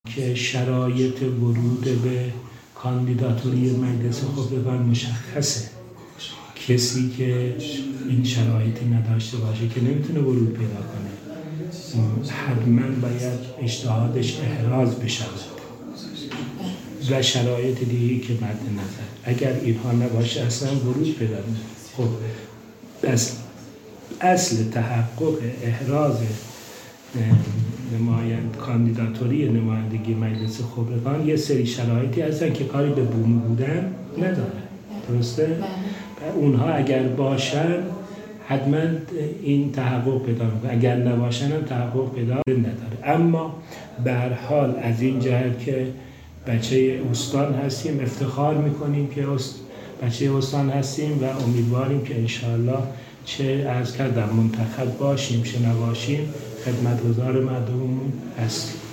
در گفت‌وگو با ایکنا از چهارمحال‌وبختیاری